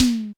Drums_K4(13).wav